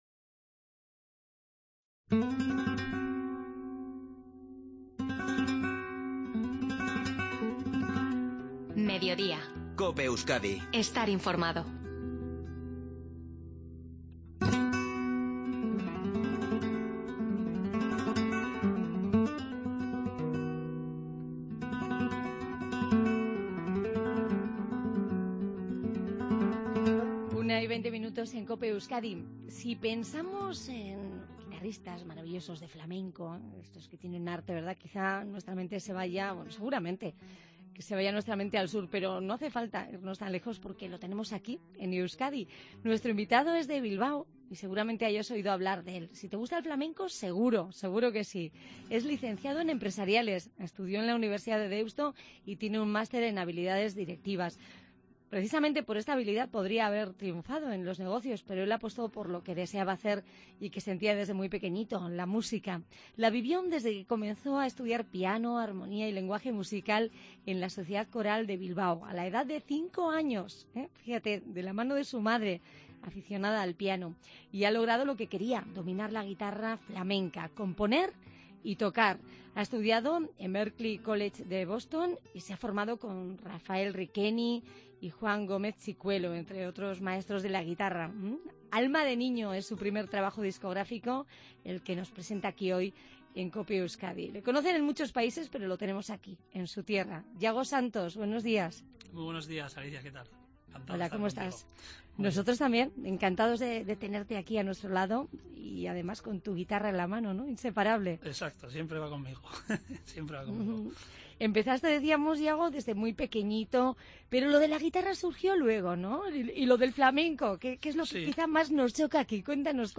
Ha tocado en directo en nuestros estudios
Puedes escuchar la entrevista y su pieza musical en directo clickando junto a la imagen en nuestros estudios.